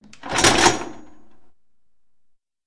clicking.mp3